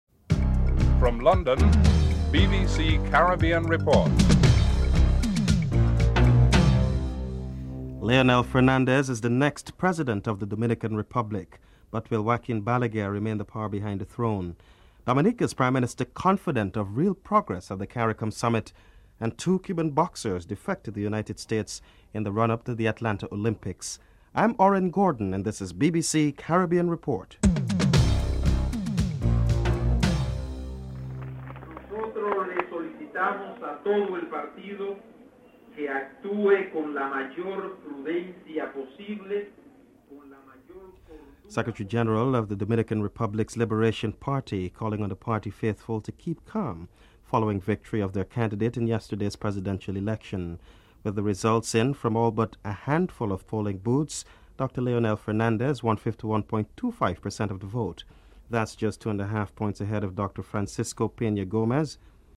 1. Headlines (00:00-00:30)